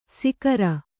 ♪ sikara